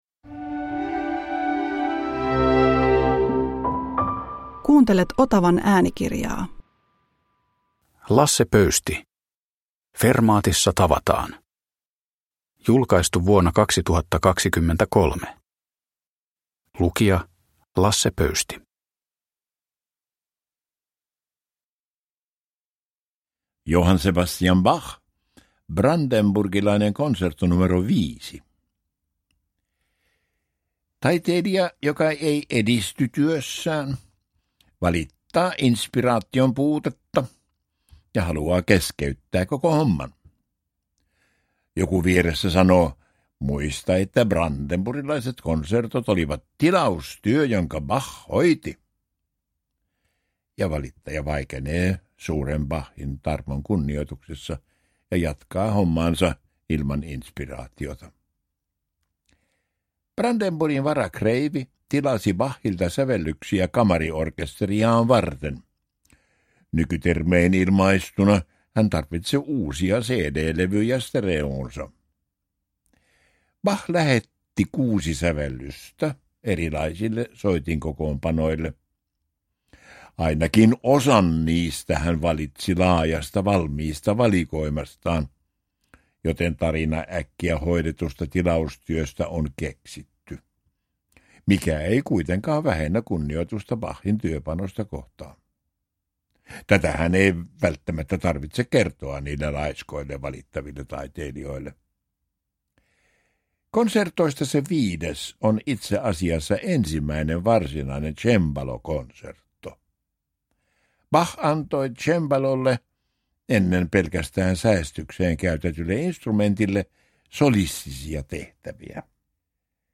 Fermaatissa tavataan – Ljudbok – Laddas ner
Uppläsare: Lasse Pöysti